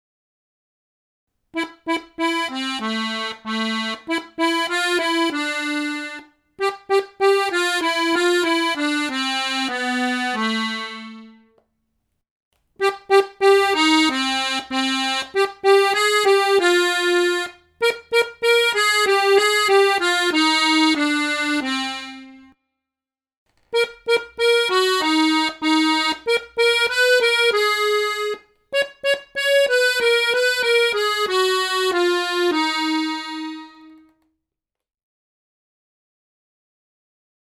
The same melody can be sung in different heights, but it is still the same.
This is what is called tonality!